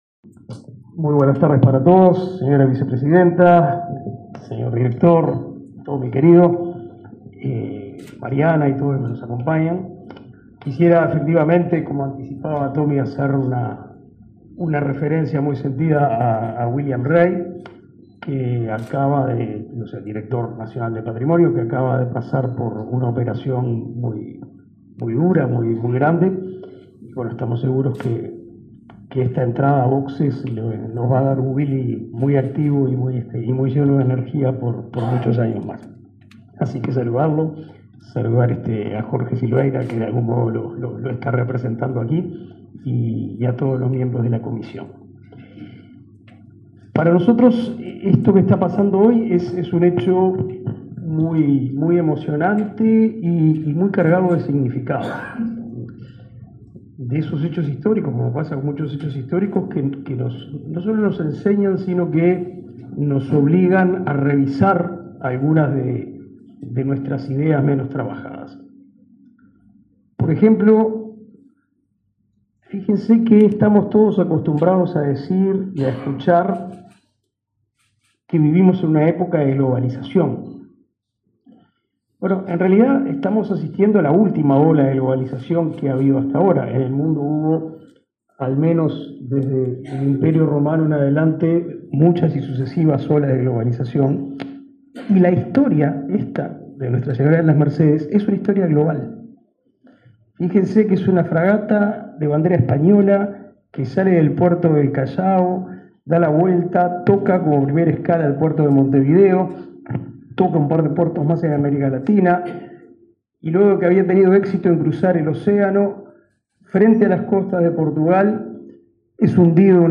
Acto por la presentación de la muestra organizada por el BCU y la Comisión de Patrimonio